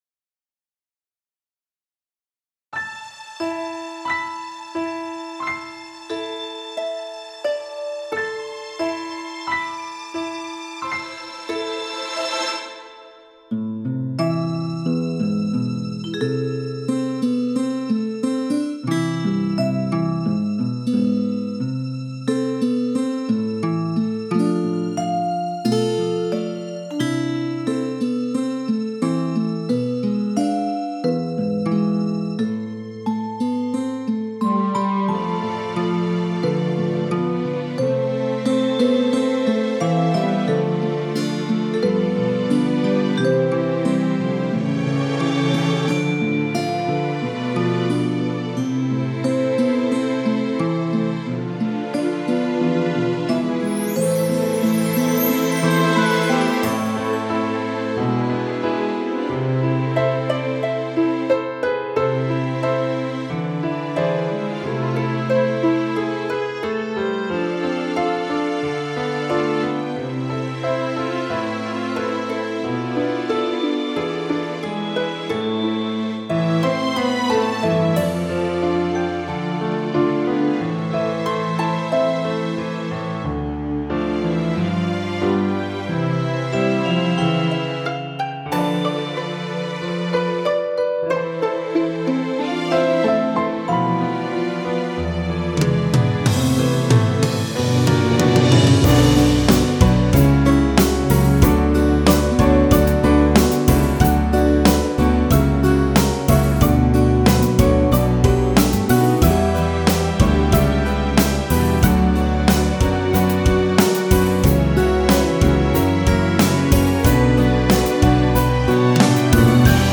И минус-заодно(может, вам тоже нравится эта песня)